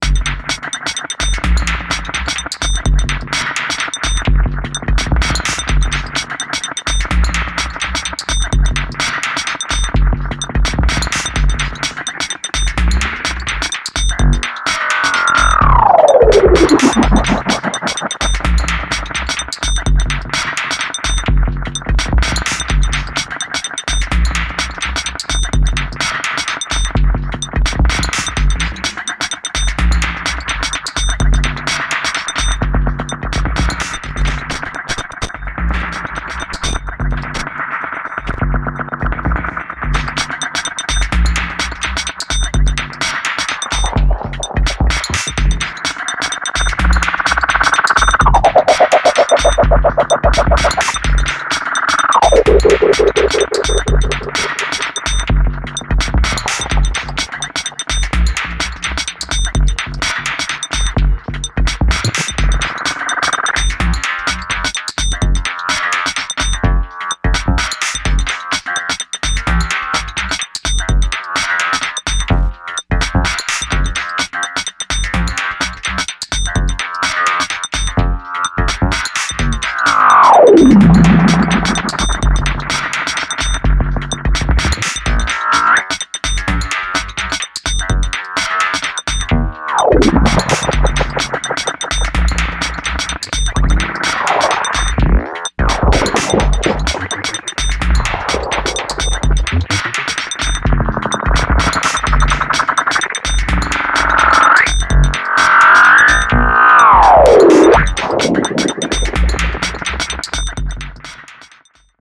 This is a sound made with my music equipment.
Modify software sound by hardware
Maxon AD-80 Delay Native Instruments REAKTOR Synthesizer / Software